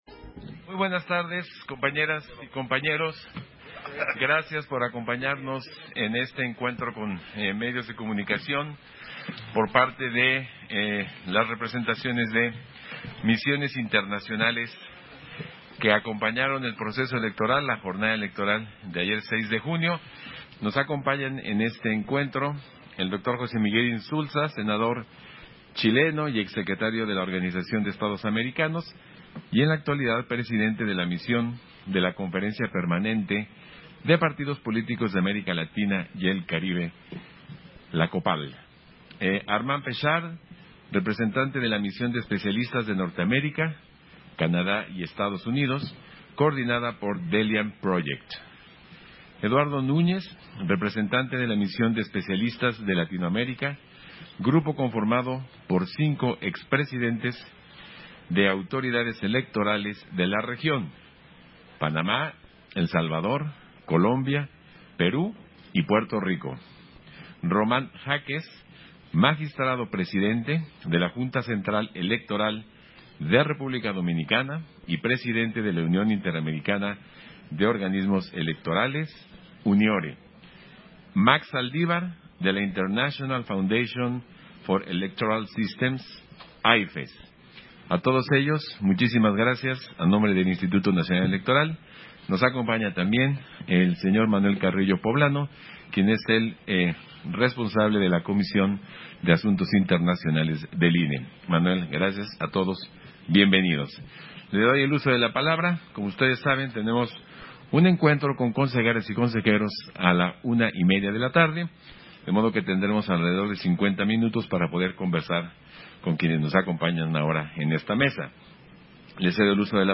Conferencia-de-prensa-con-Observadores-Electorales.mp3